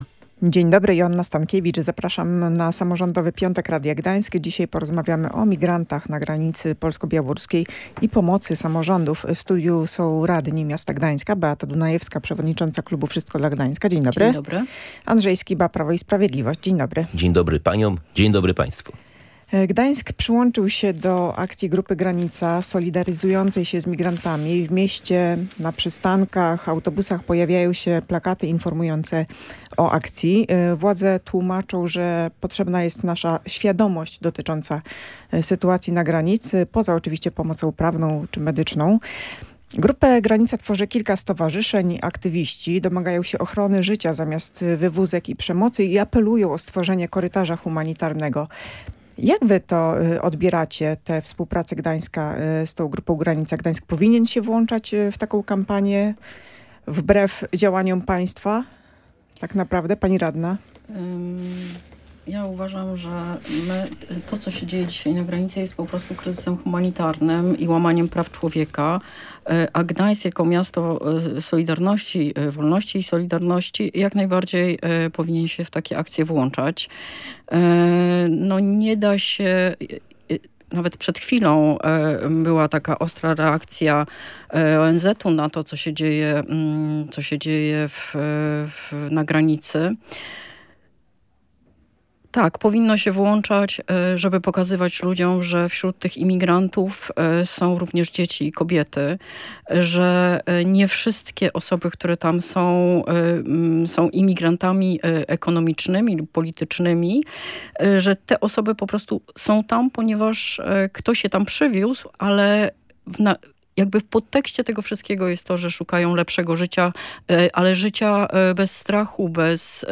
W „Samorządowym Piątku” swoje opinie na ten temat wyrazili radni: Beata Dunajewska z ugrupowania Wszystko dla Gdańska i Andrzej Skiba z Prawa i Sprawiedliwości.